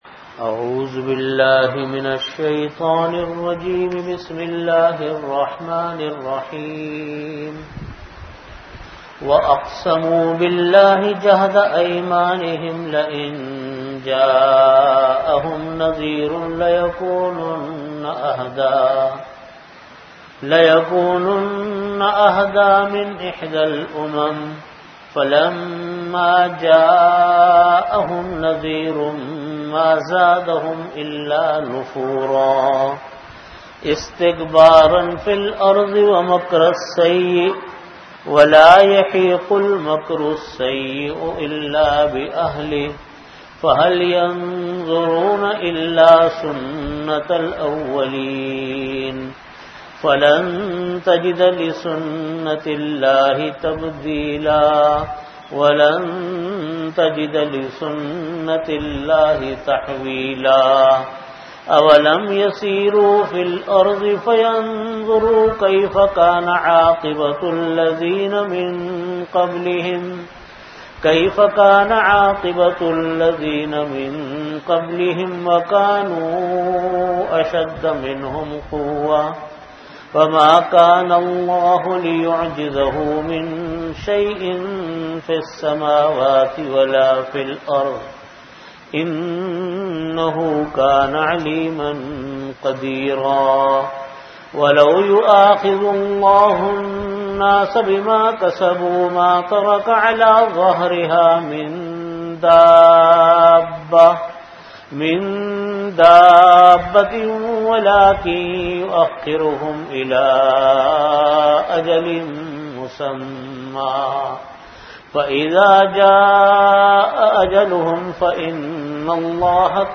Audio Category: Tafseer
Time: After Fajr Prayer Venue: Jamia Masjid Bait-ul-Mukkaram, Karachi